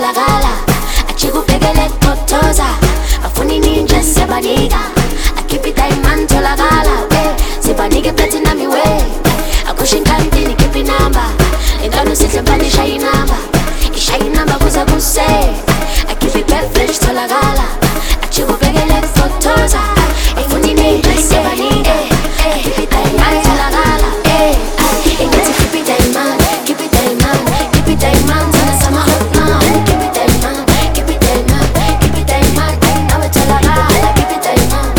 Жанр: Африканская музыка / Поп
# Afro-Pop